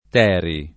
teri [ t $ ri ]